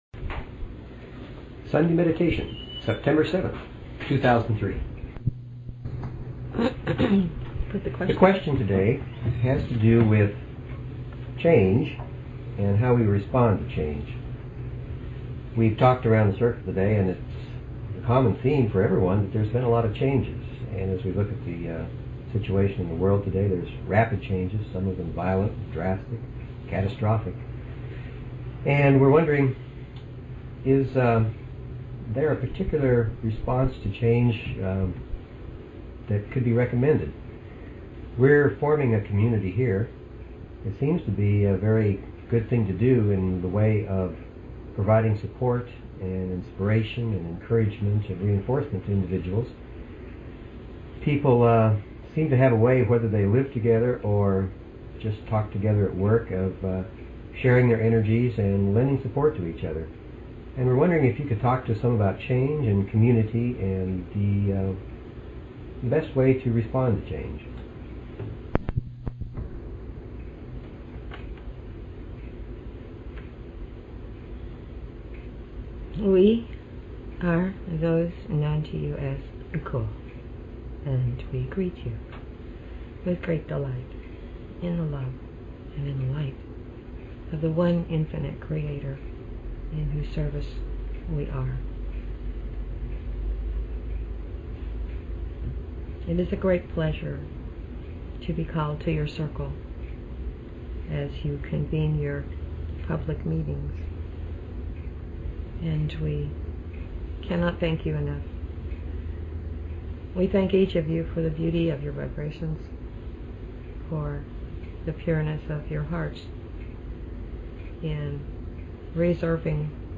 Show Headline LLResearch_Quo_Communications Show Sub Headline Courtesy of BBS Radio September 7, 2003 Group question: The question today has to do with change and how we respond to change.